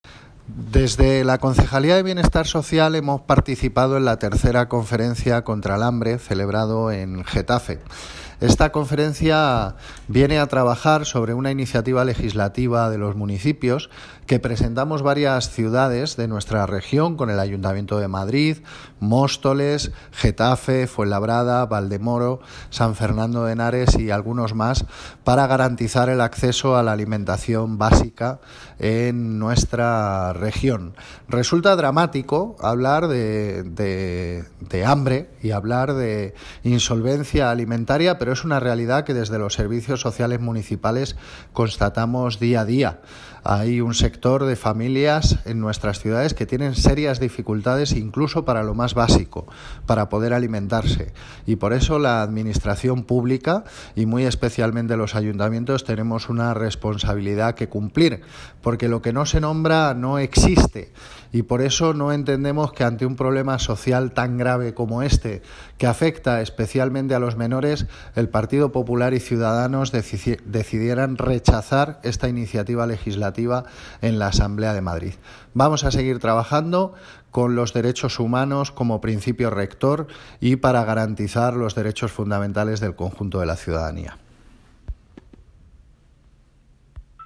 Audio - Gabriel Ortega (Concejal de Cultura, Bienestar Social y Vivienda) Sobre Conferencia contra el Hambre